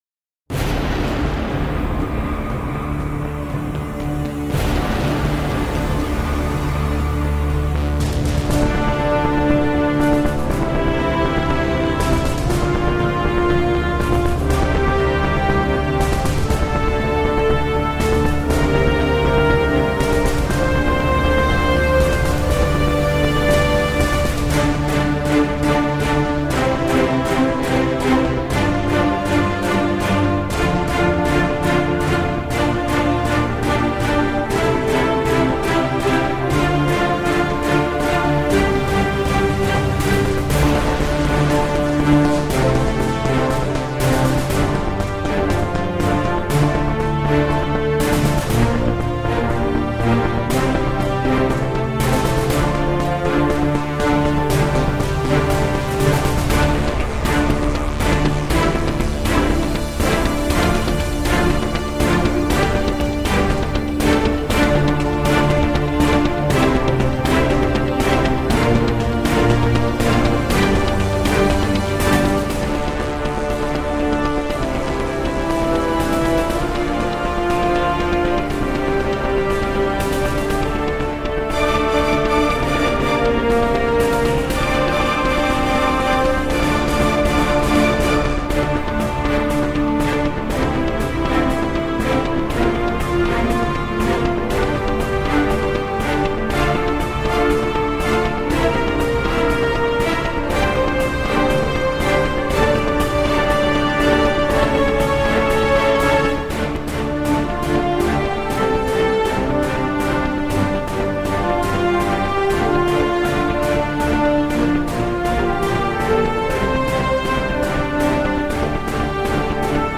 Action epic track for first-person shooter.